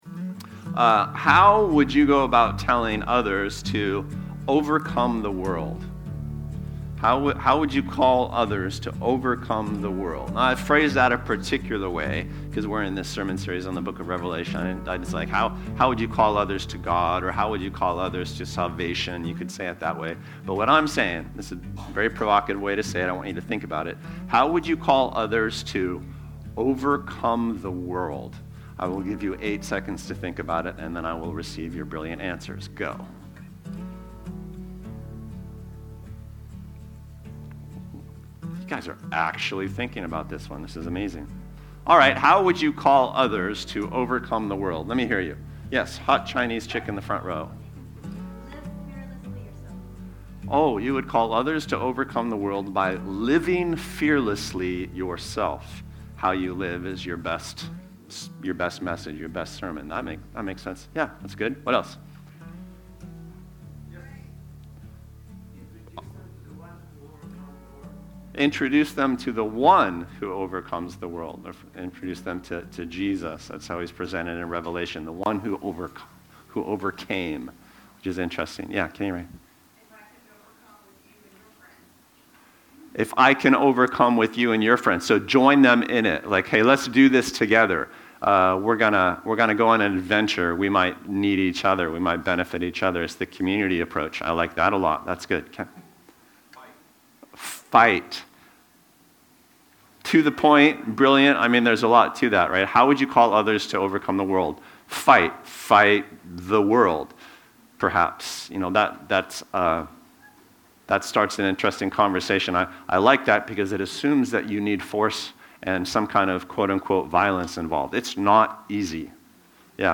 Podcasts of Bluewater Mission sermons, updated weekly.